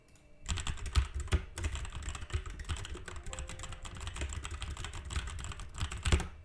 Po pierwsze, klawisze klawiatury są głośne.
Dźwięki klawiszy Genesis RX85 RGB
Genesis-RX85-RGB-dzwiek-klawiszy.mp3